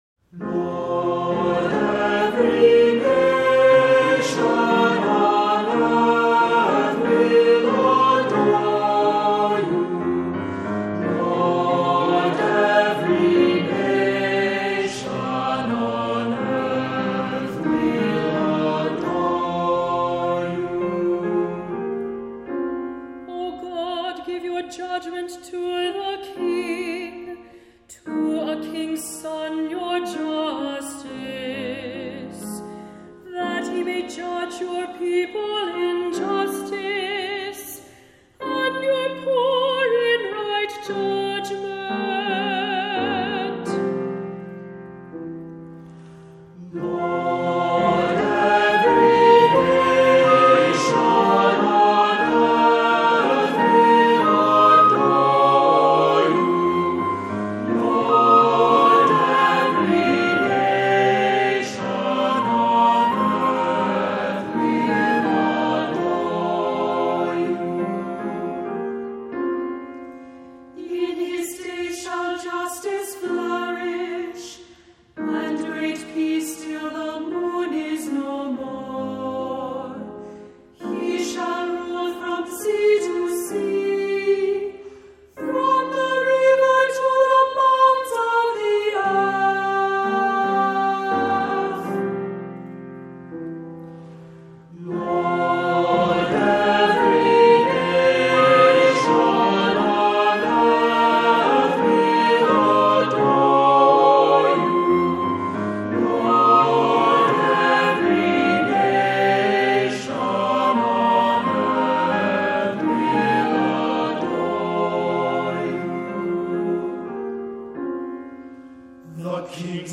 Voicing: SATB; Cantor; Assembly